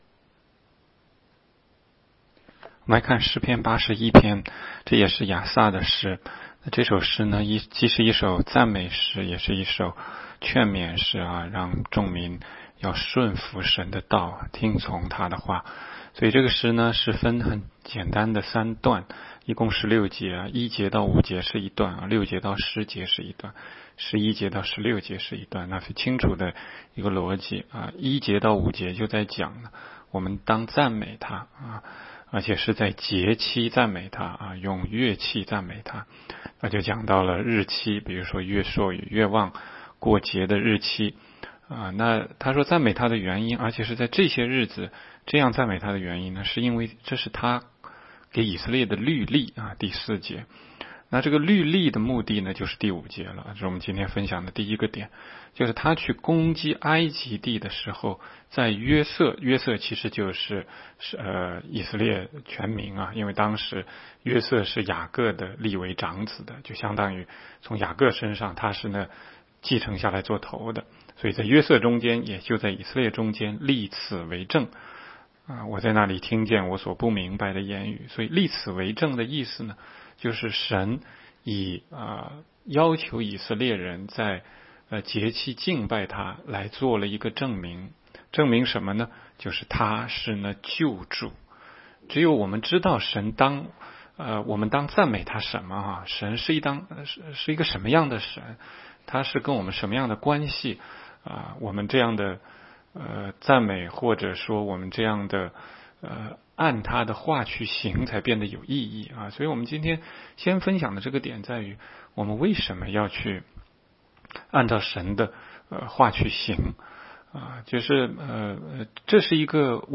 16街讲道录音 - 每日读经-《诗篇》81章